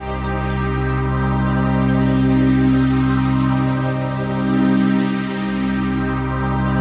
DM PAD1-03.wav